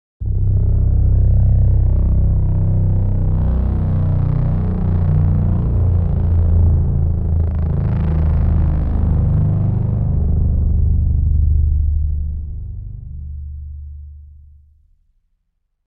Deep Large Drone Flyover Sound Effect
Description: Deep large drone flyover sound effect. Dramatic rumble, terrifying sound of a large drone. Sci-fi futuristic sound.
Deep-large-drone-flyover-sound-effect.mp3